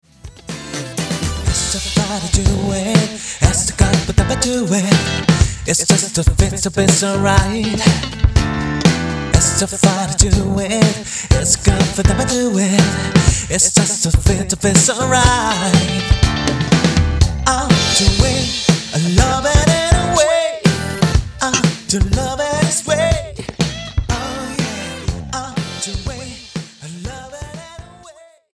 가사도 없이 잘도 조잘대는구나!